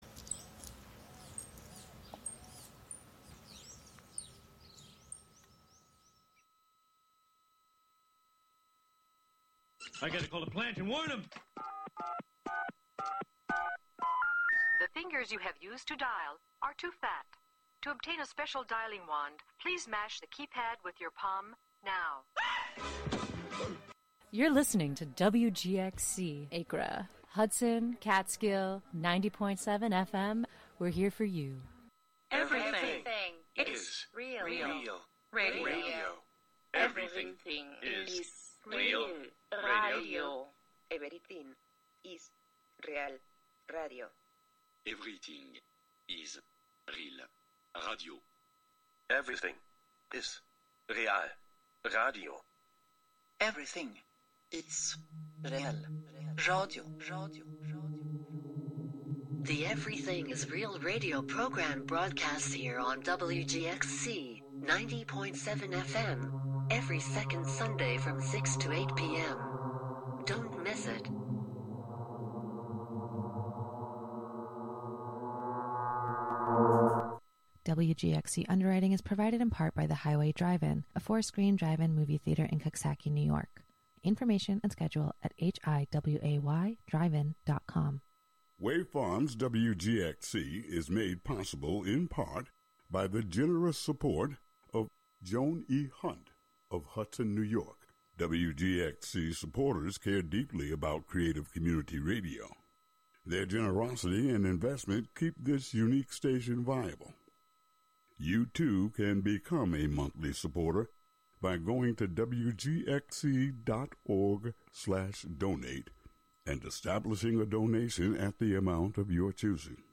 brings you sounds from raves and clubs around the world